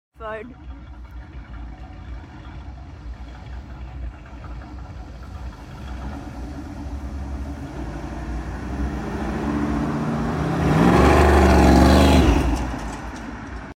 Crazy cammed truck rips